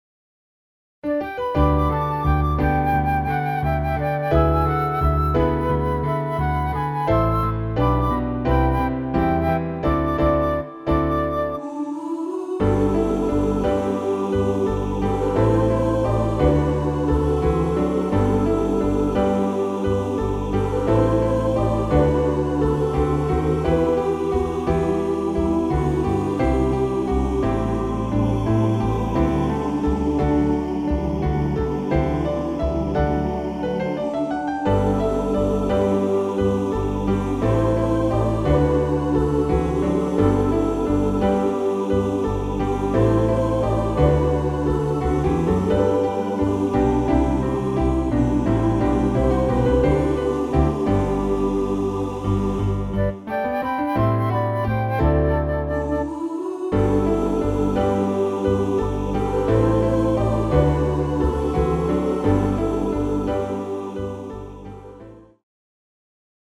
Version (B) Chor/Klavier/2 Querfl./Kb. (Computermusik)
(Sanctus)
Klavier + Querfl.